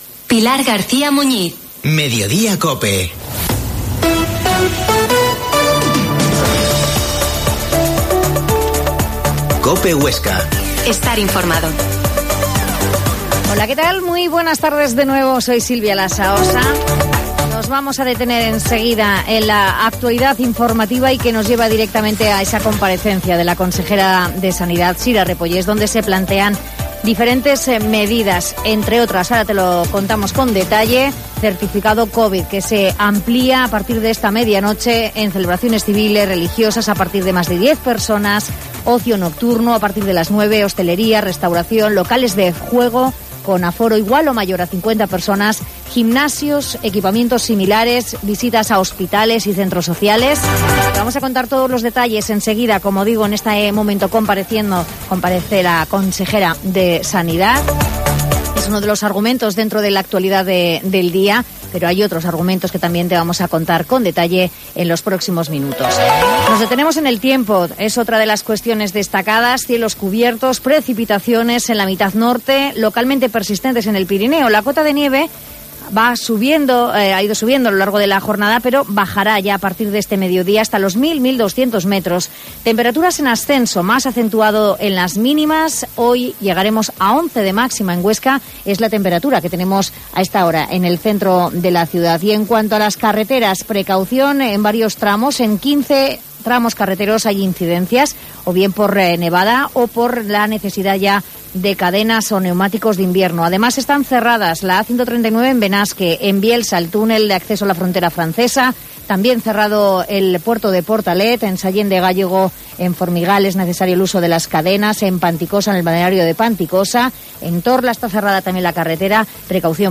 La Mañana en COPE Huesca - Informativo local Mediodía en Cope Huesca 13,20h.